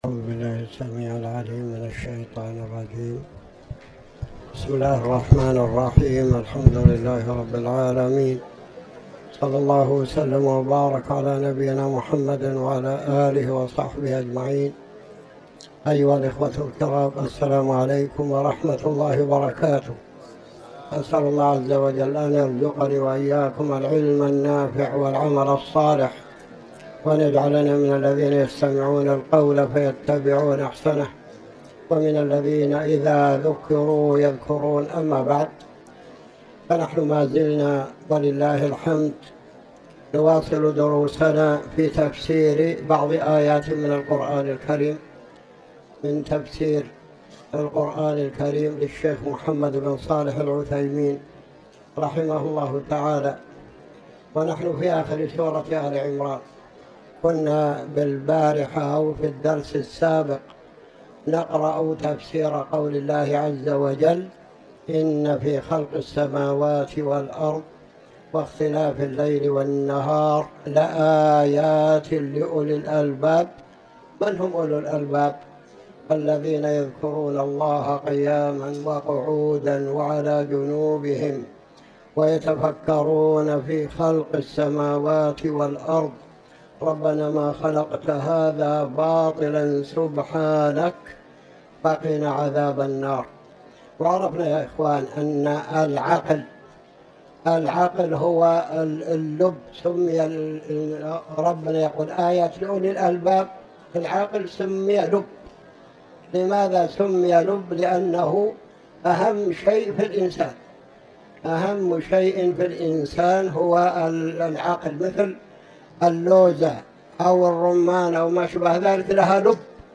تاريخ النشر ٢٠ رجب ١٤٤٠ هـ المكان: المسجد الحرام الشيخ